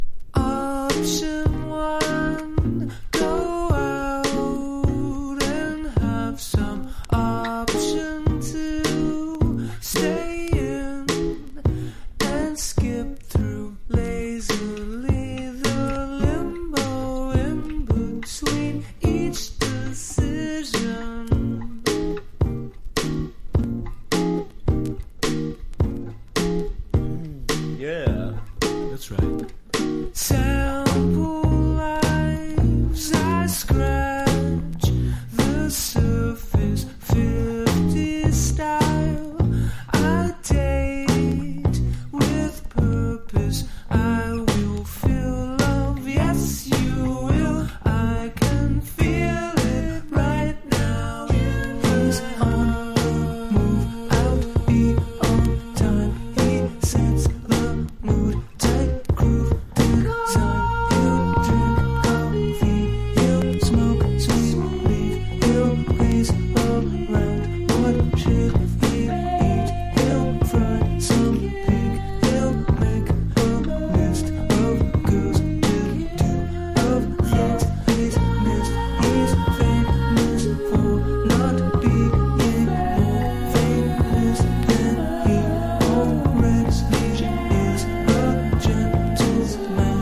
洗練されたグルーヴィー・シンセ・ポップ。艷っぽくクールネスで格好良い!!
# ELECTRO POP